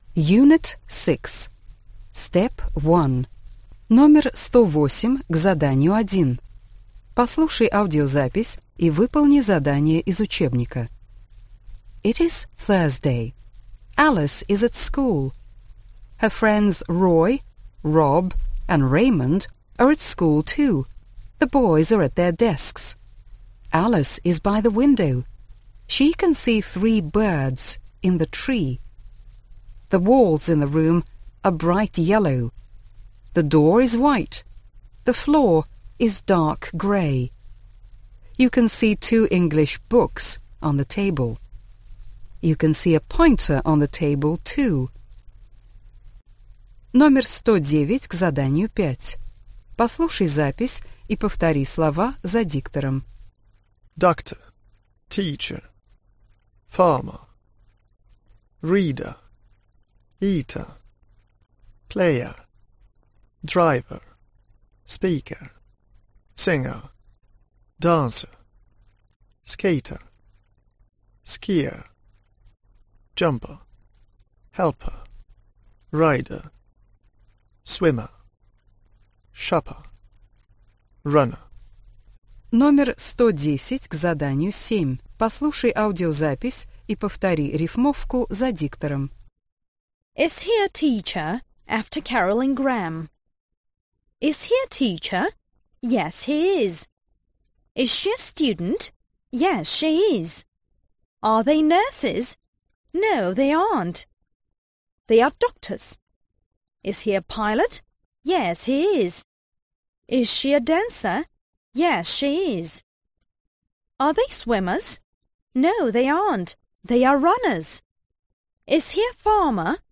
Аудиокнига Английский язык. 3 класс. Аудиоприложение к учебнику часть 2 | Библиотека аудиокниг